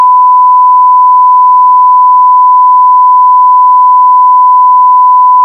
Too much noise in data using PCM4201 A/D - Audio forum - Audio - TI E2E support forums
The file was sample at 64Khz, and is attached.